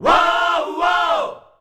UUAAUUAAH.wav